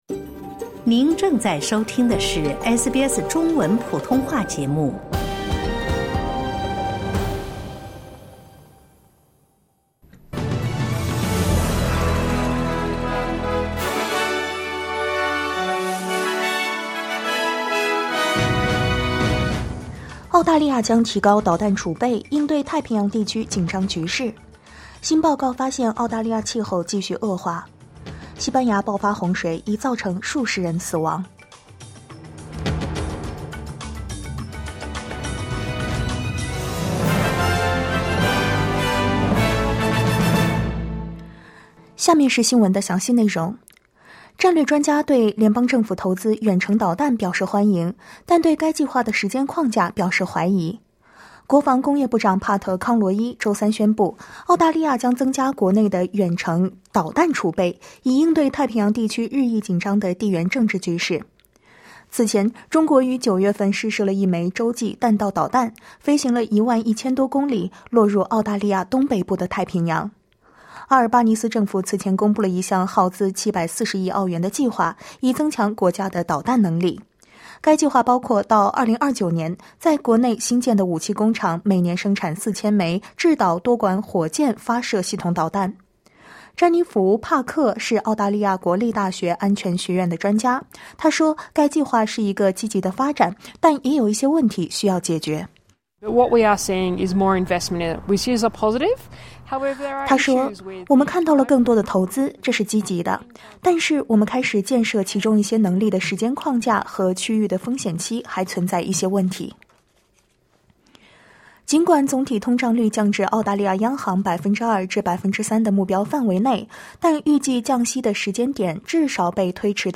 SBS早新闻（2024年10月31日）